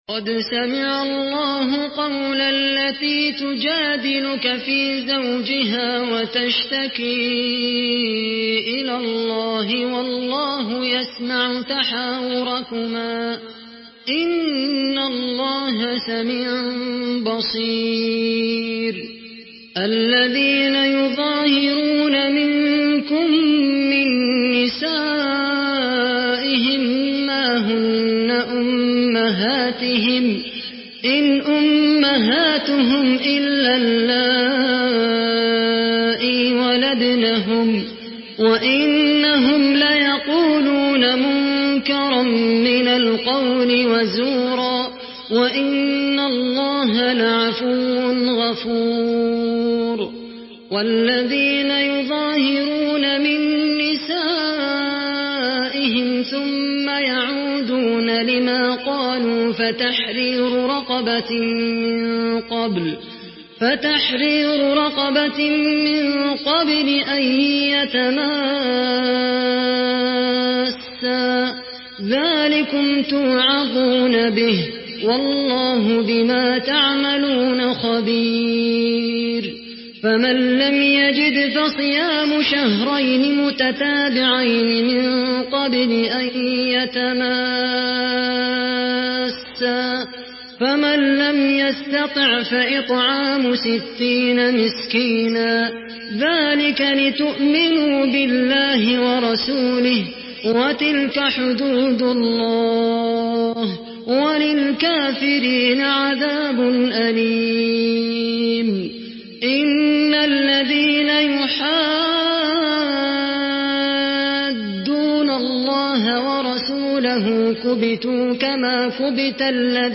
Surah المجادله MP3 in the Voice of خالد القحطاني in حفص Narration
Listen and download the full recitation in MP3 format via direct and fast links in multiple qualities to your mobile phone.
مرتل حفص عن عاصم